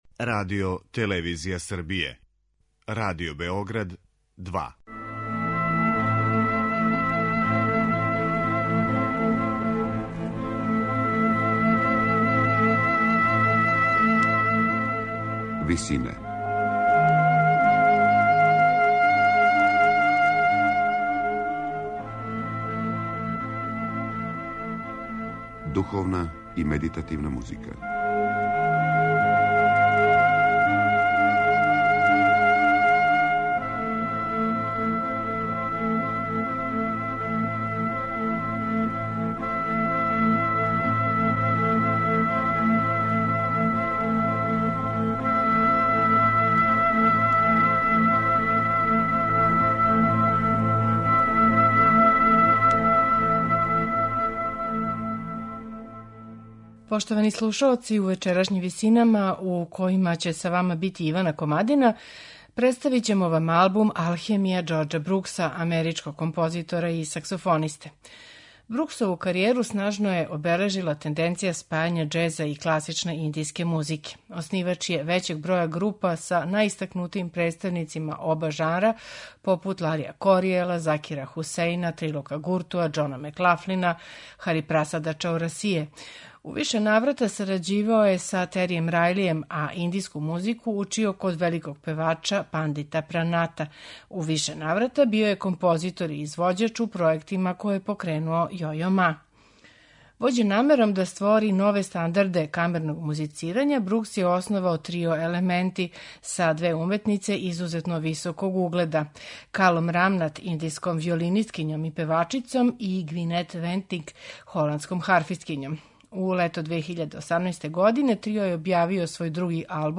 спајања џеза и класичне индијске музике